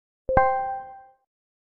Звук беспроводных наушников Apple AirPods Pro 2 и других в mp3 для монтажа
5. Включение режима прозрачности AirPods
airpods-rejym-prozrachnosty-vk.mp3